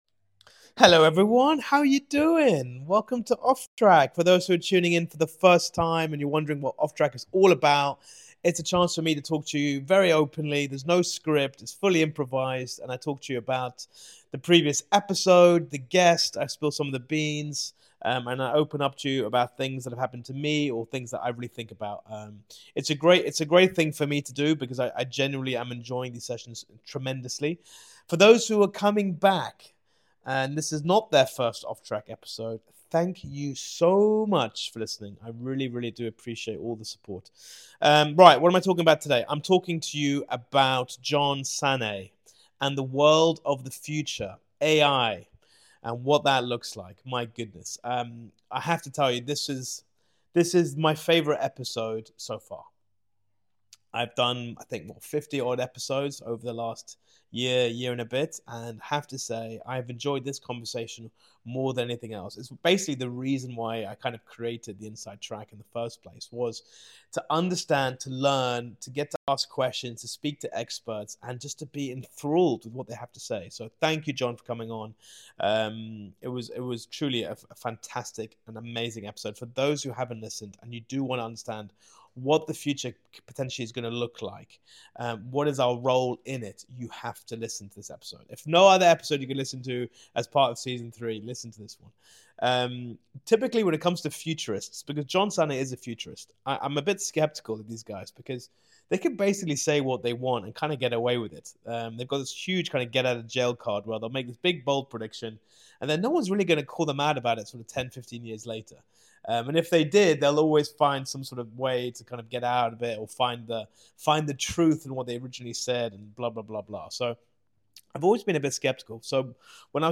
Get ready to gain a fresh perspective, all done over a cup of coffee and authentic conversation.
From exciting interviews to insightful discussions, these candid conversations aim to uncover the insights and inspirations that drive people to push boundaries, overcome obstacles, and lead transformative lives.